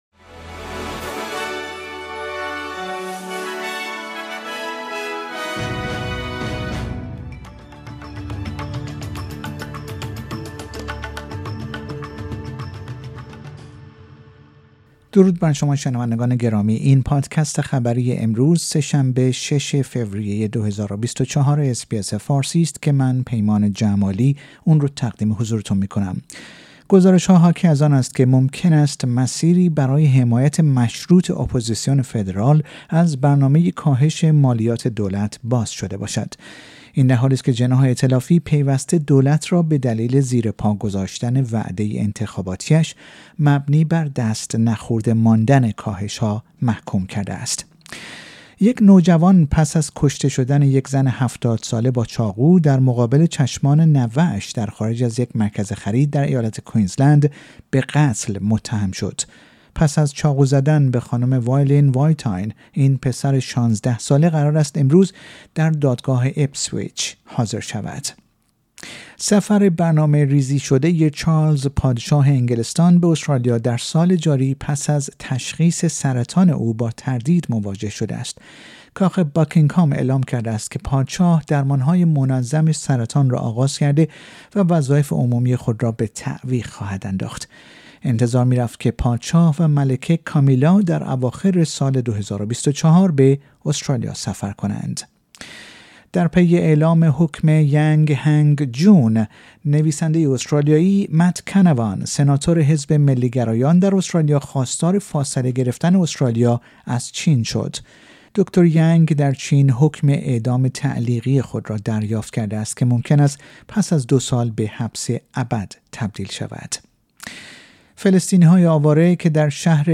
در این پادکست خبری مهمترین اخبار استرالیا و جهان در روز سه شنبه ۶ فوریه ۲۰۲۴ ارائه شده است.